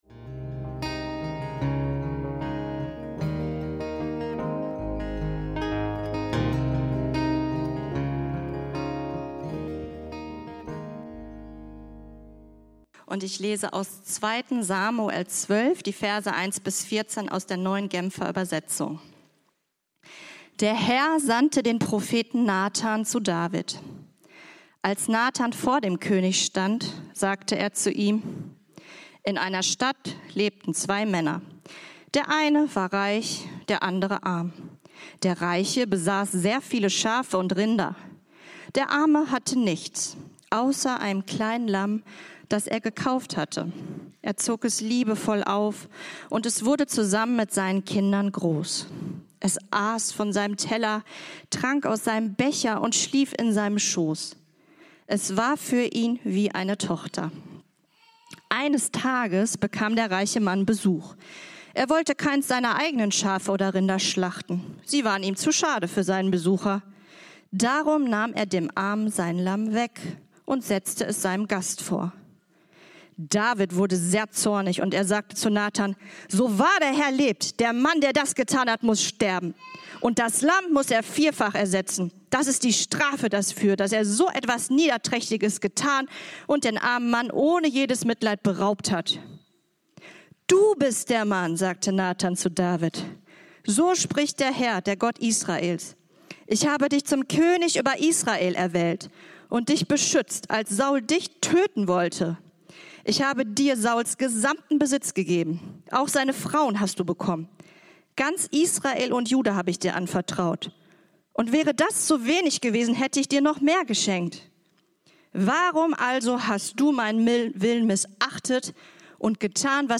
Menschen begegnen dem lebendigen Gott: König David und seine größte Verfehlung - Predigt vom 18.05.2025